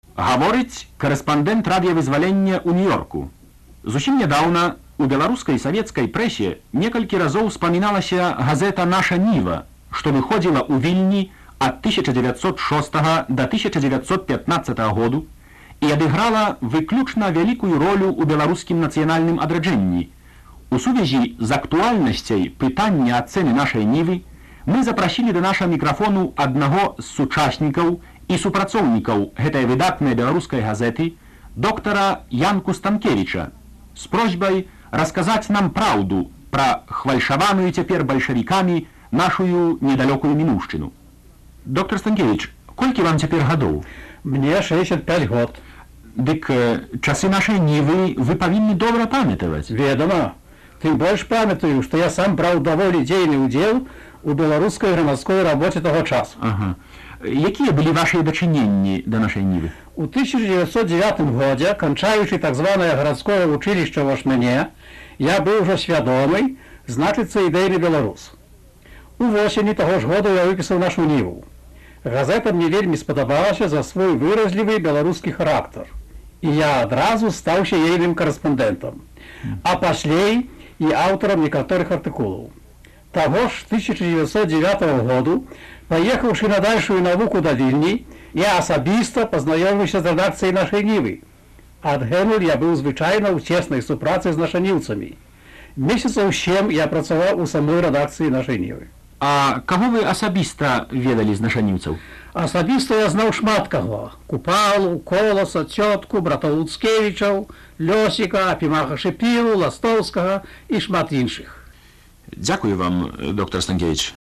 1956. Інтэрвію зь Янкам Станкевічам